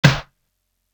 Simmons Snare.wav